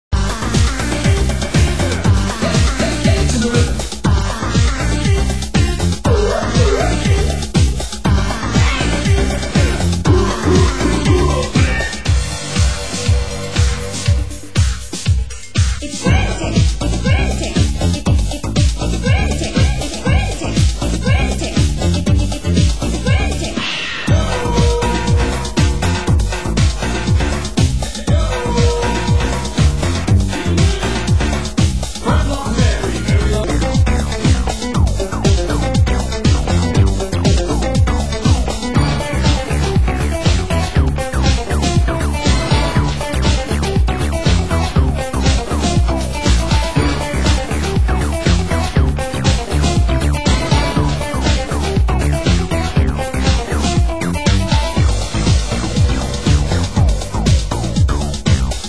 Genre: Acid House